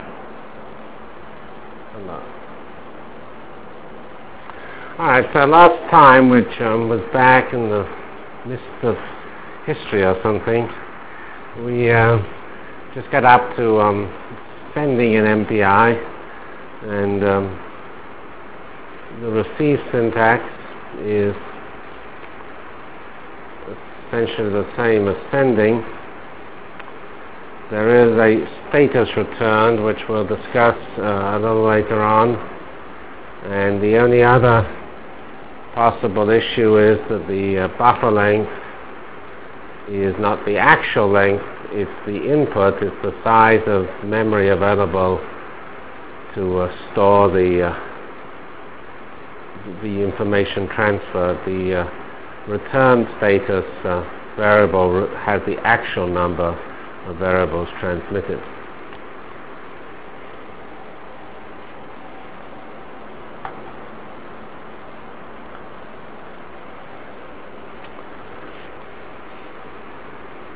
Lecture of November 7 - 1996